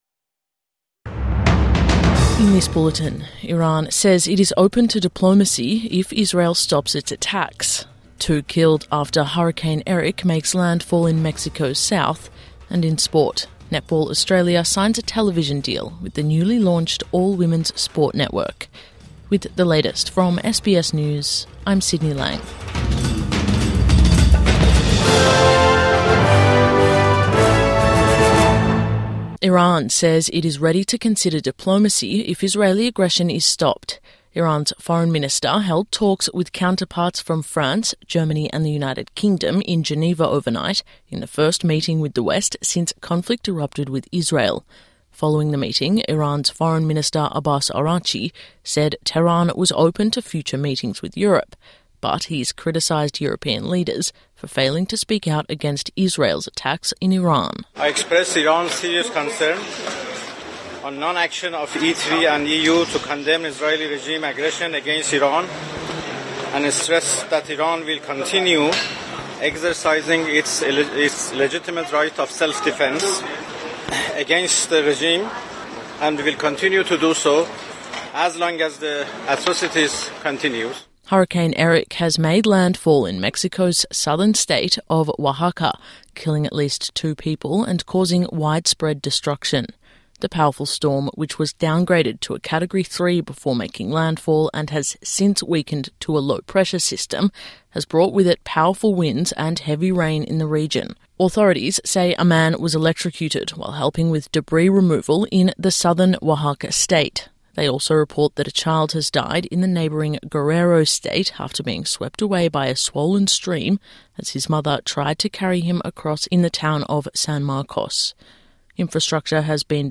Morning News Bulletin 21 June 2025